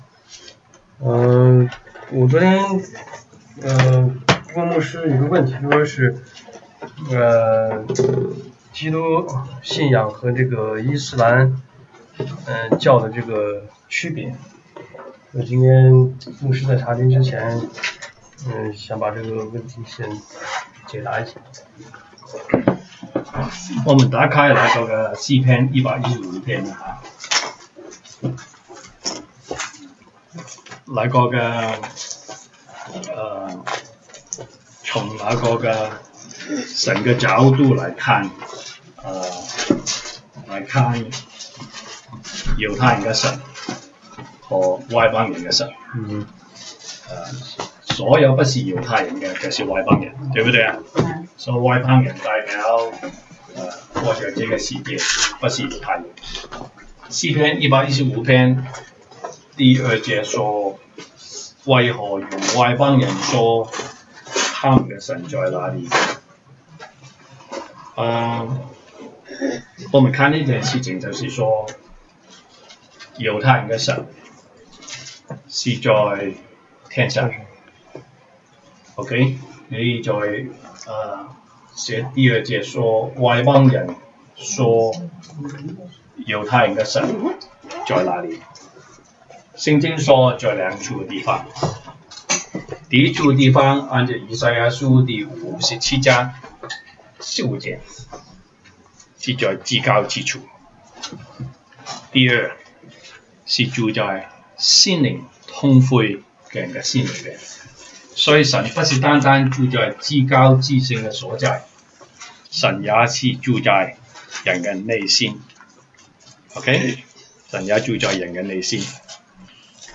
週一國語研經 Monday Bible Study « 東北堂證道